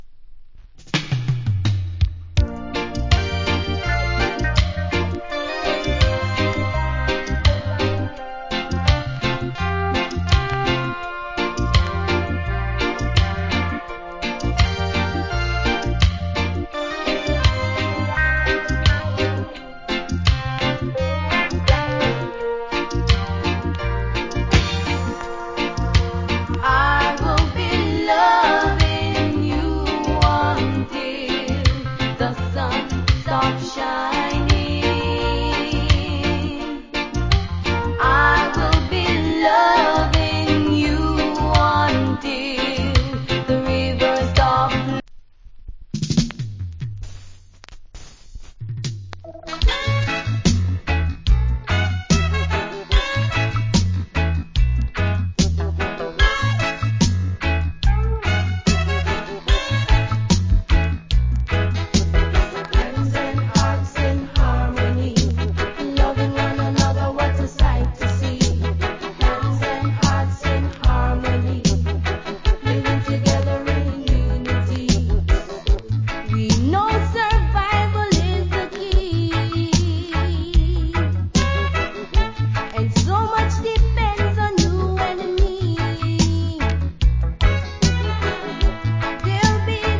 80's. Nice Female Reggae Vocal.